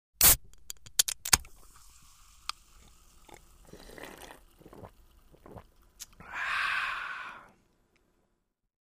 Звуки еды
Открывают банку газировки, делают глоток, выдыхают